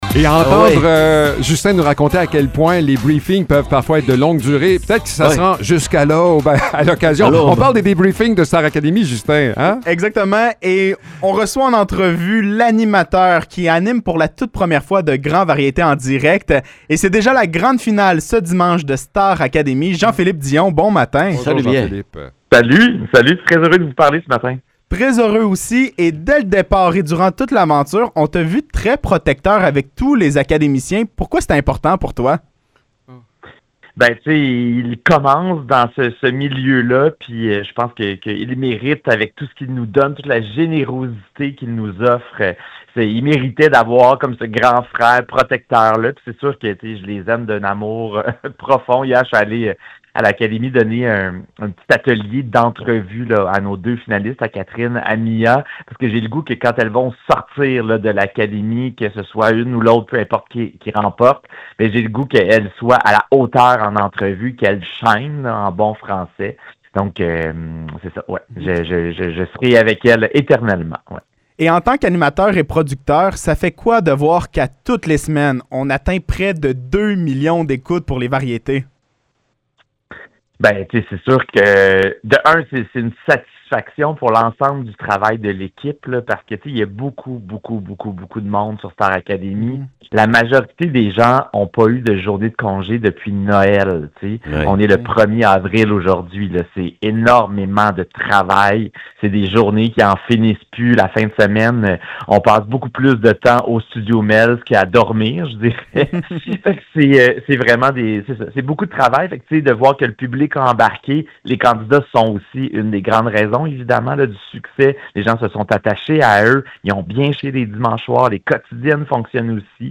Entrevue avec Jean-Philippe Dion